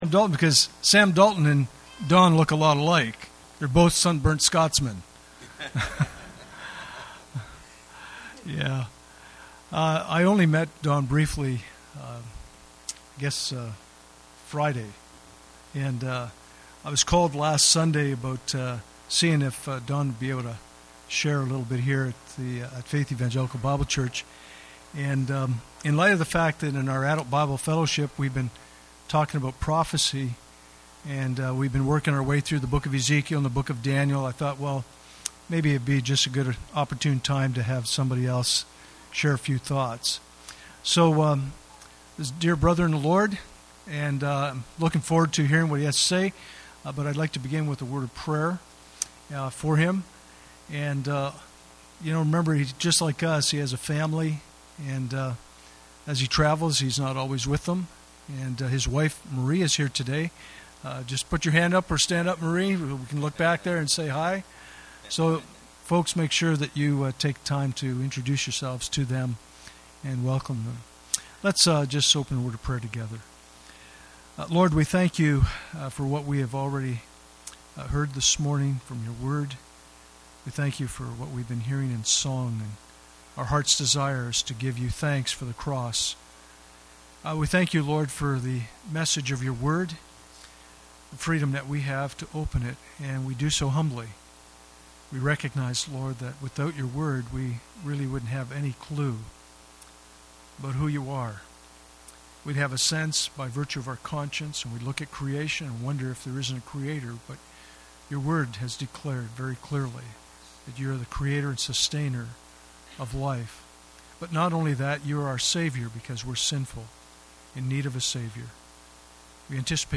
Passage: Ephesians 6:10-19 Service Type: Sunday Morning « Communion Service Living in an Eroding Culture